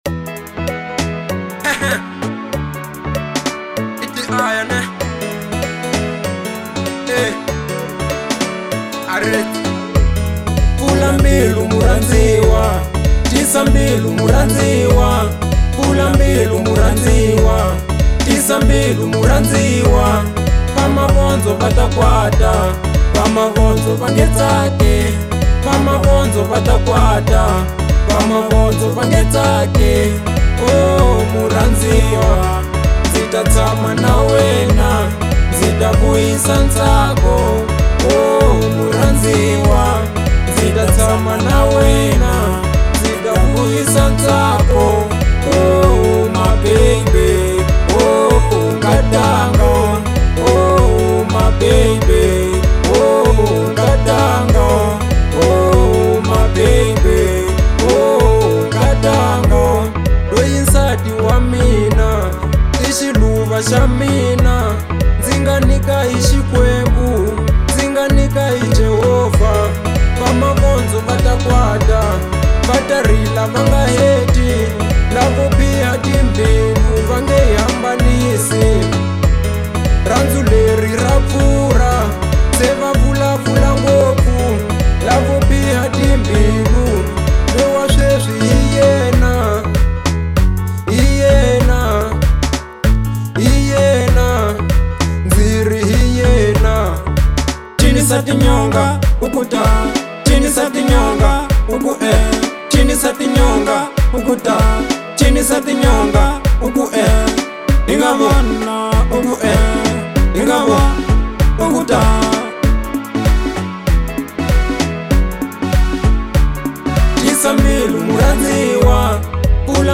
02:53 Genre : African Disco Size